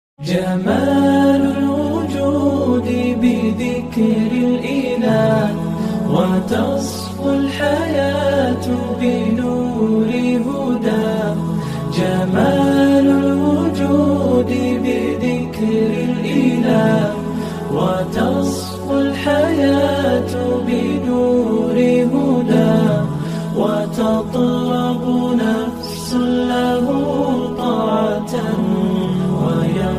Popular Islamic nasheed ringtone
with spiritual tone.